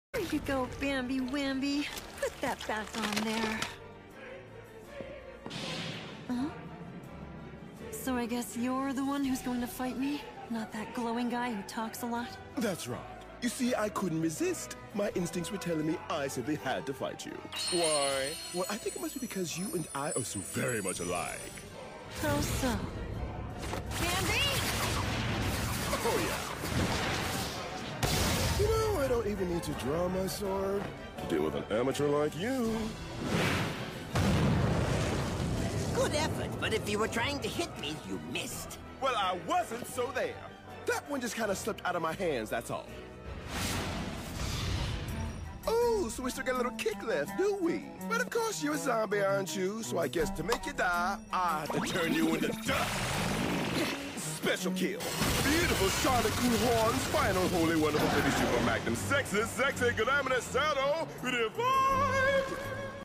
Bro sounds so funny dubbed